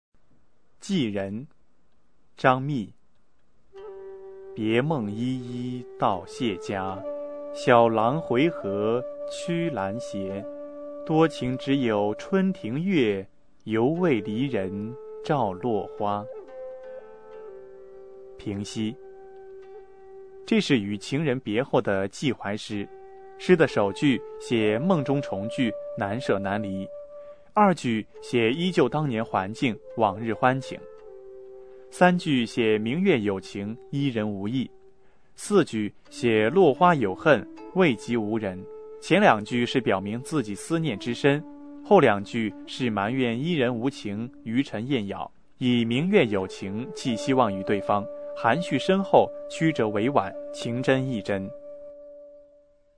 张泌《寄人》原文、译文、赏析、朗读